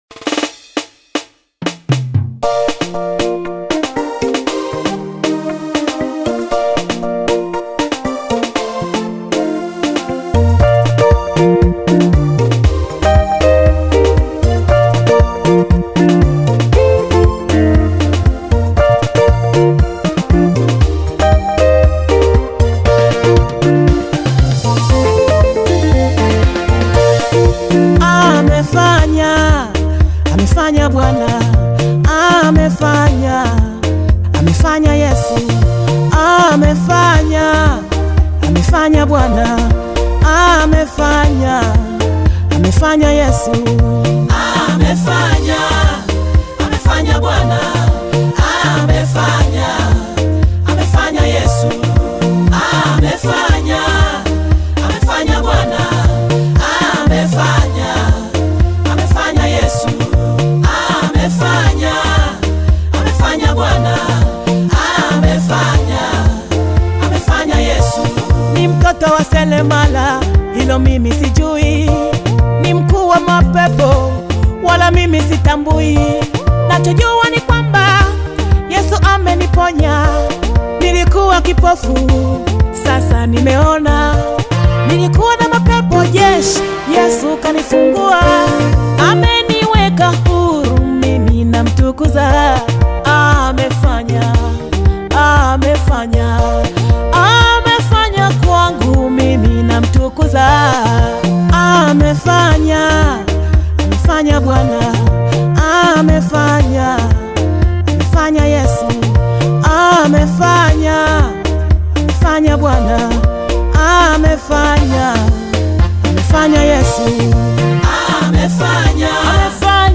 is a heartfelt Tanzanian gospel song